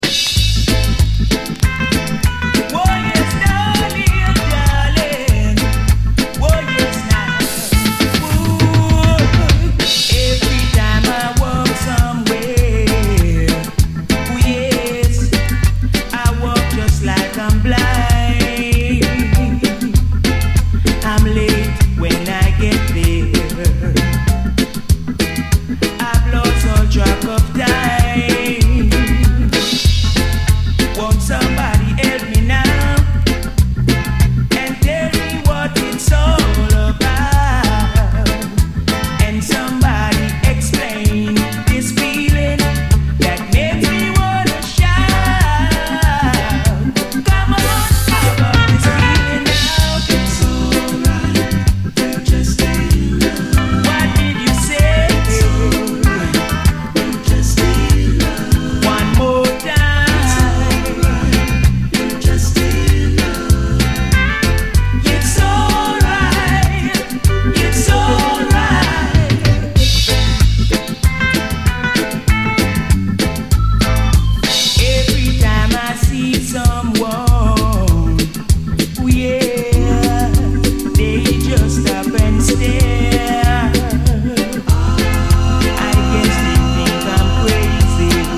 サビでの解放感もビューティフル。
（歌い出し部分で一瞬入るノイズのようなものは、全てのコピーで入るプレス自体に起因するものです。